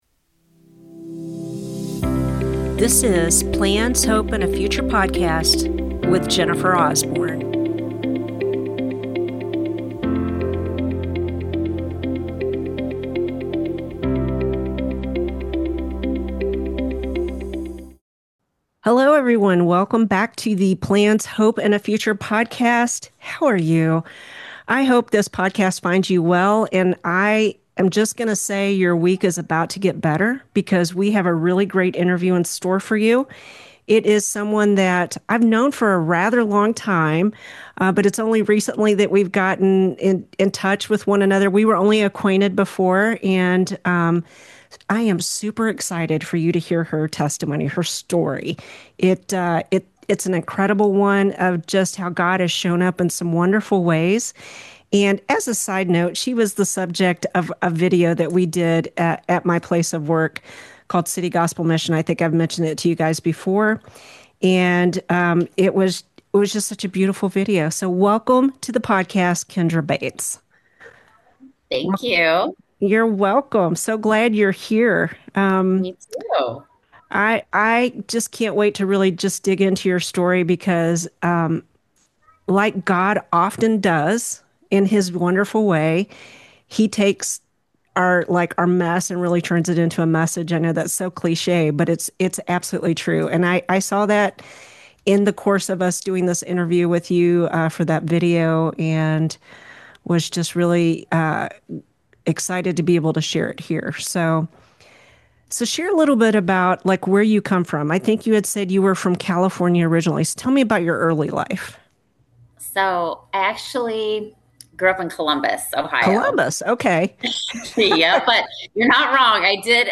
A conversation